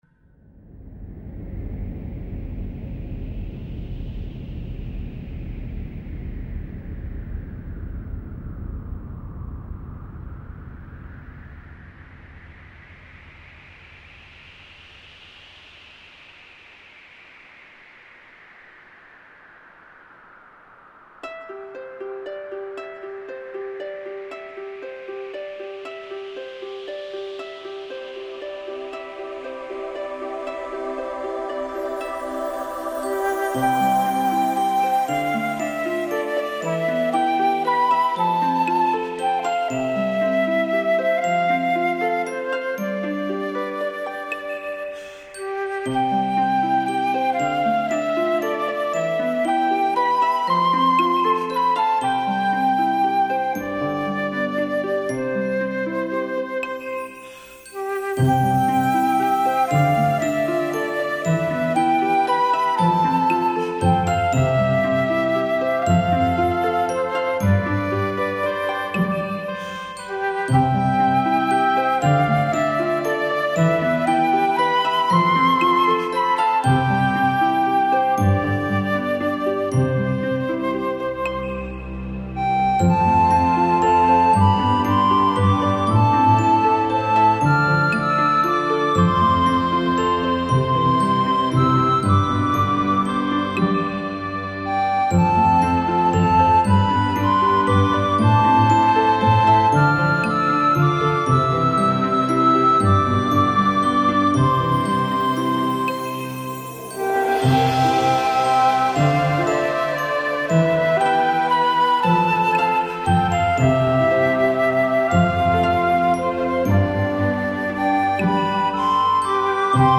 دانلود آهنگ بیکلام
عالی و زیبا و ارامش بخش